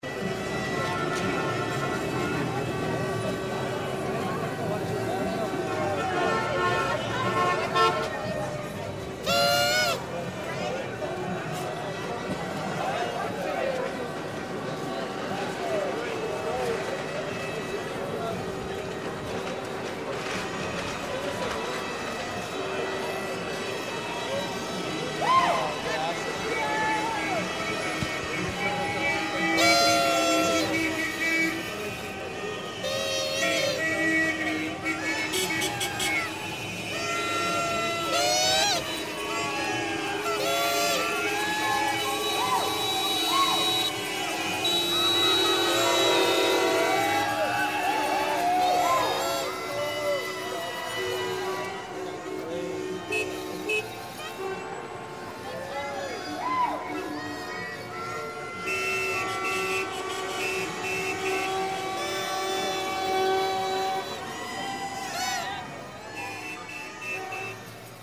10. Городская вечеринка на улице с дуками, сигналами машин